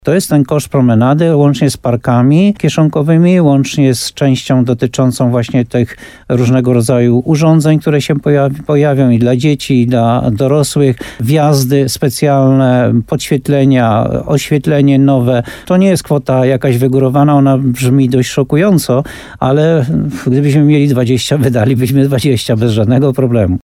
W Złockiem, w gminie Muszyna powstanie promenada spacerowa. – Koncepcja jest gotowa, pieniądze też są zabezpieczone – mówi burmistrz Jan Golba.